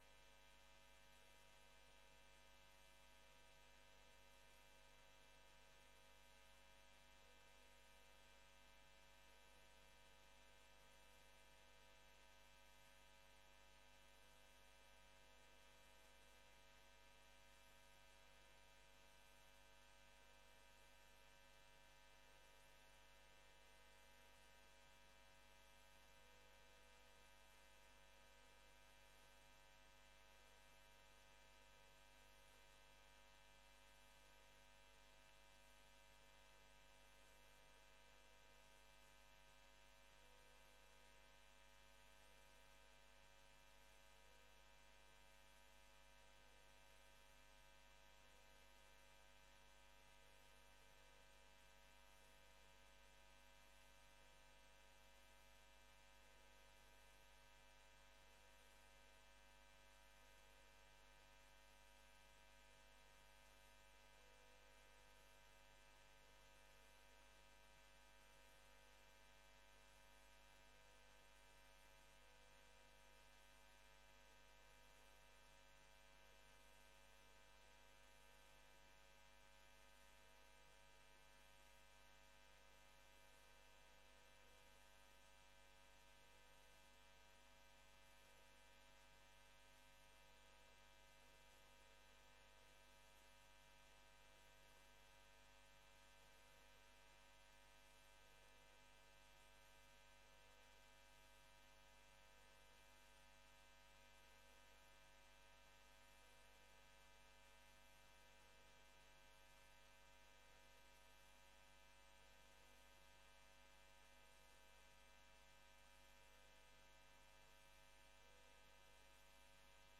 Beeldvormend - Gemeenteraad Sliedrecht 09 september 2025 19:30:00, Gemeente Sliedrecht
Download de volledige audio van deze vergadering